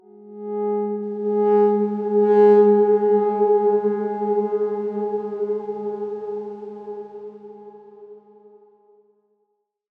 X_Darkswarm-G#3-mf.wav